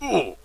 受击.mp3